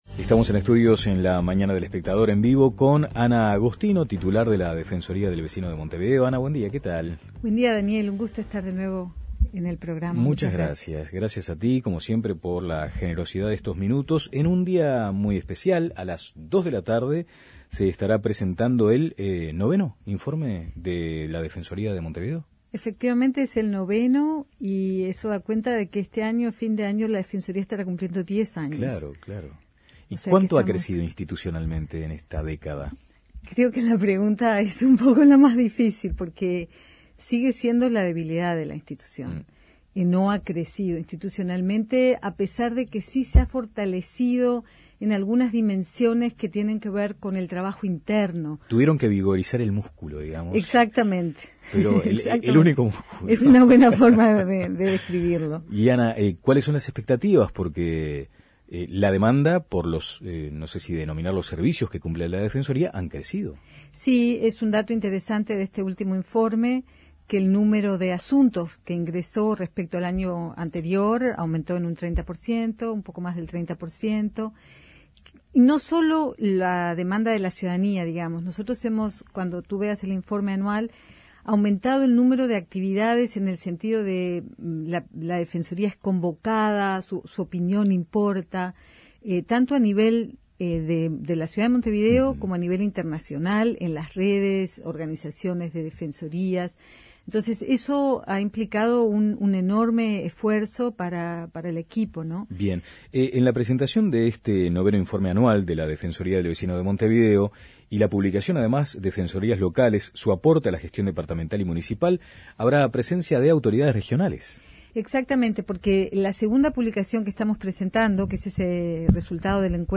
Escuche la entrevista completa aquí: Ana Agostino en La Mañana Descargar Audio no soportado